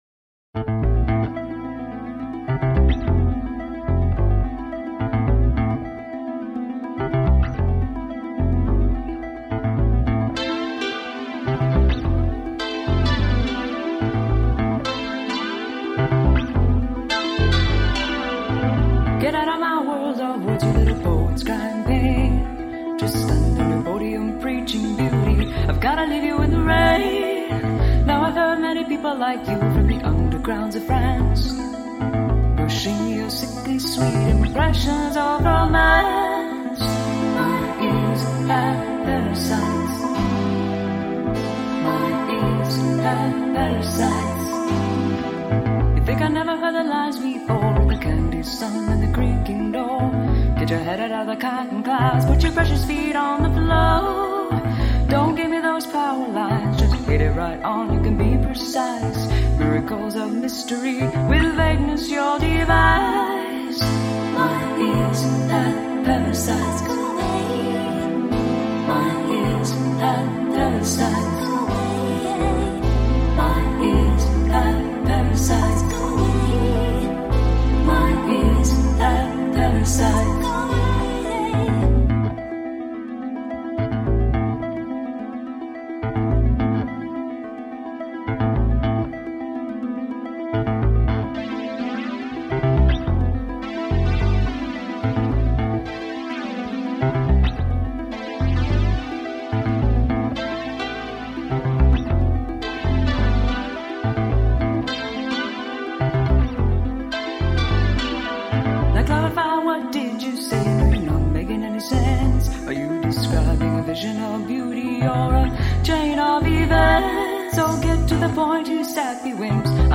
guitar/vocal or guitar/vocal/electronics